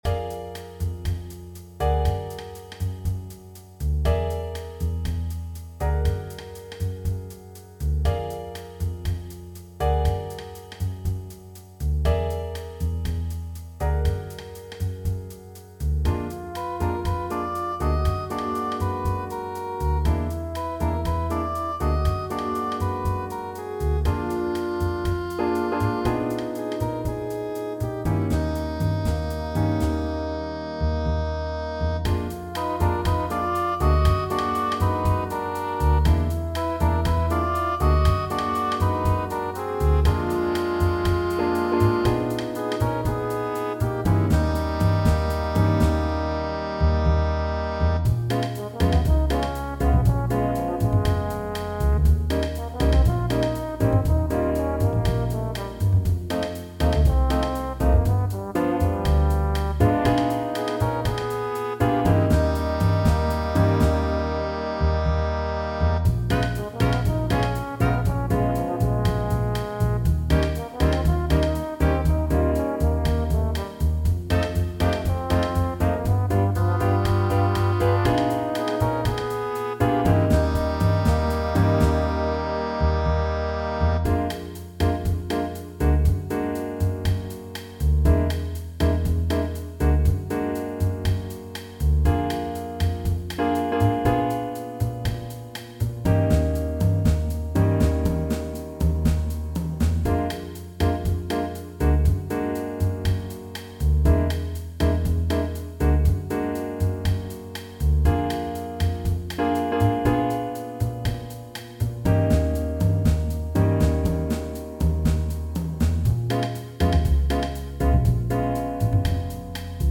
All audio files are computer-generated.
Trumpet, Sax, Trombone, Piano, Bass, Drums
Six-piece arrangements with improvisation